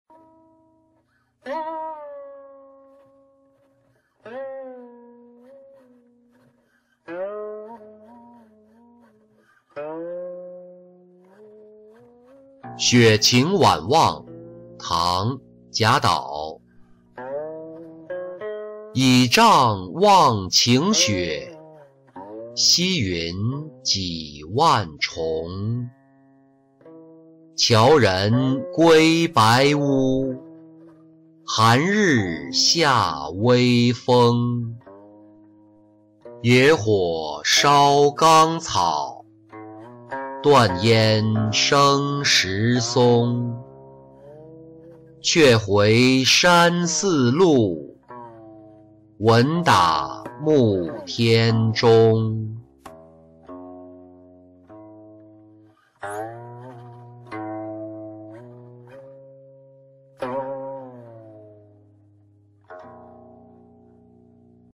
雪晴晚望-音频朗读